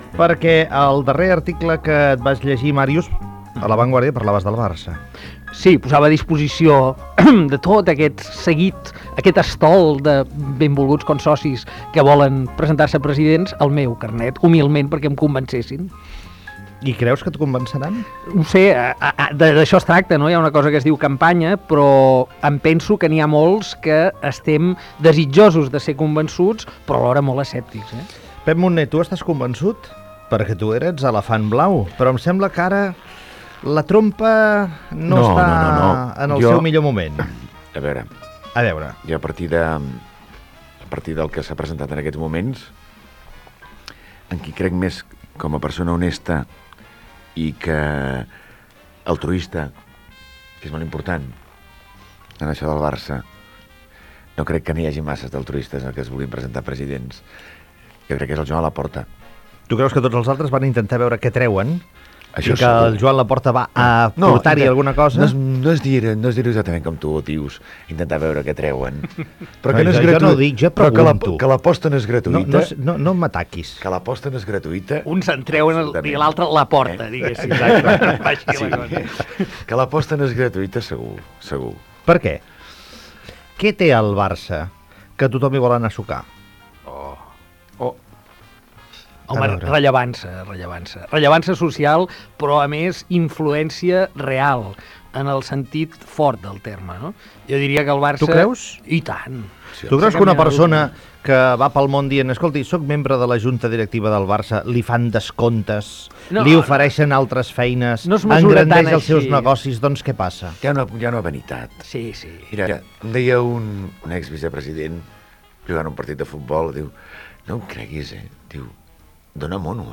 608a2a26d069f117d56c7e230c588f64c6c0bc47.mp3 Títol Ona Catalana Emissora Ona Catalana Cadena Ona Catalana Titularitat Privada nacional Nom programa Els matins de Josep Cuní Descripció Conversa amb l'escriptor Màrius Serra i l'actor Pep Munné sobre les eleccions a la presidència del Futbol Club Barcelona. Invitació a participar en el redactat del final d'una novel·la de Màrius Serra que s'ha anat publicant a "La Vanguardia".
Info-entreteniment